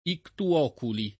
ictu oculi [lat. & ktu 0 kuli ] locuz. avv.